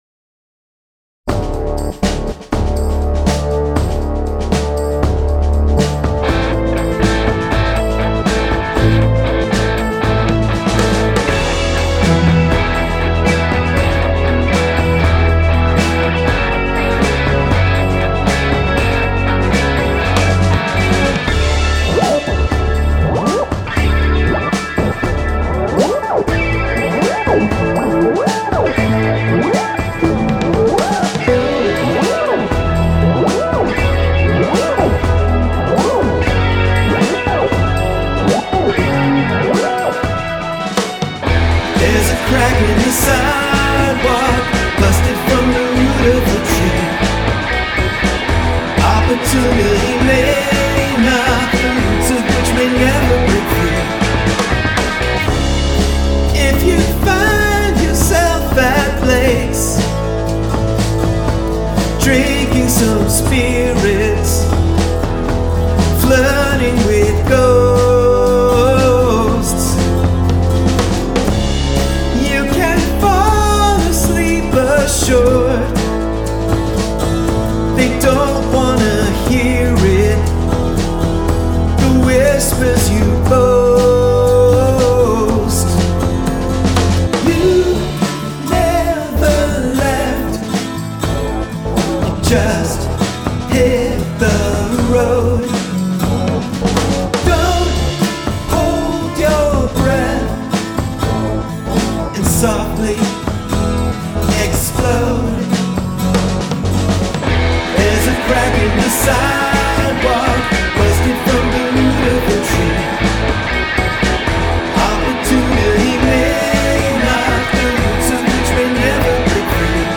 SpoilerIntro in ⅝
Chorus in ⅞
Verse in 4/4
Pre-Chorus in 5/8
Break it Down in ⅝
Double Chorus in 7/8